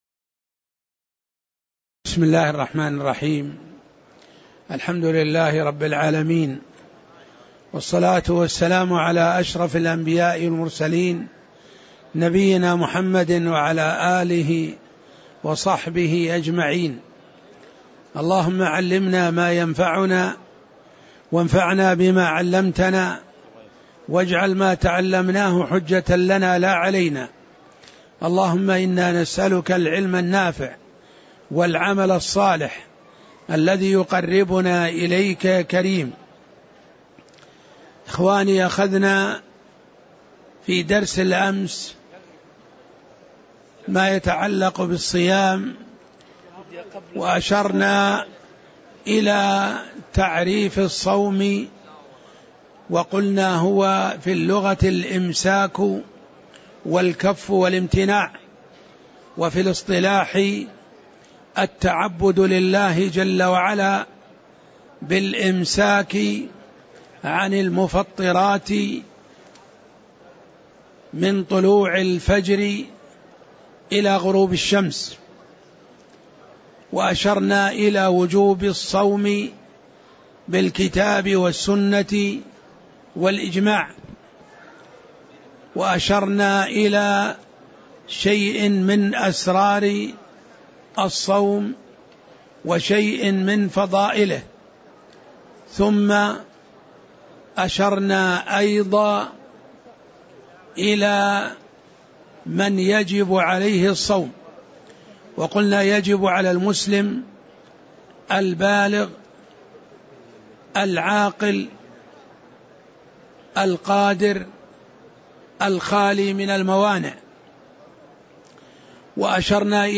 تاريخ النشر ١٩ شعبان ١٤٣٧ هـ المكان: المسجد النبوي الشيخ